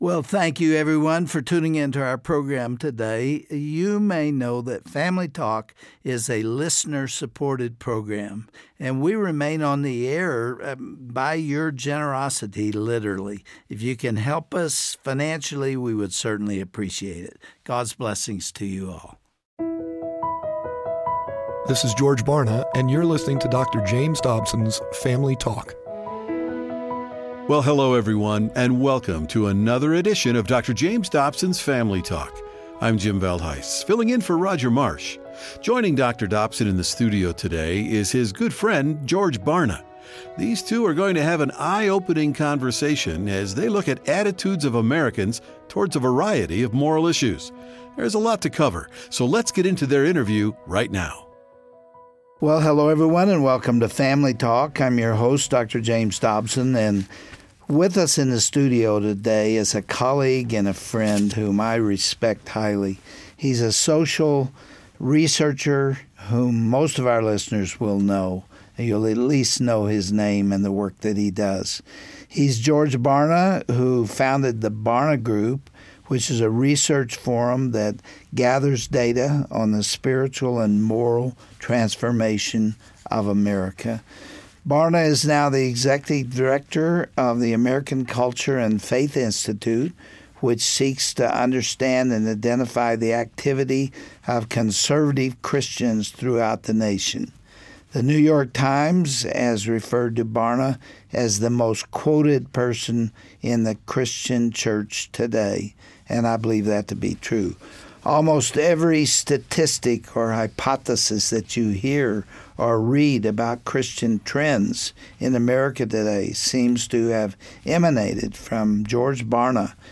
Social and moral values in this next generation are drastically moving away from Judeo-Christian beliefs. Today Dr. Dobson welcomes back to the Family Talk studios, well-known researcher and author George Barna, to discuss the data confirming this frightening trend. The two will unpack how the meaning of life molds ones worldview and the need for a Biblical awakening in this generation, on this edition of Dr. James Dobsons Family Talk.